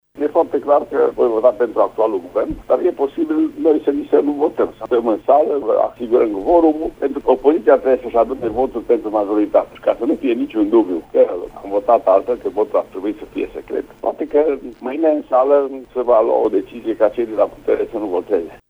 Parlamentariii PSD ar putea să nu participe mâine la vot, asigurând doar cvorumul, a declarat președintele PSD Mureș, Vasile Gliga: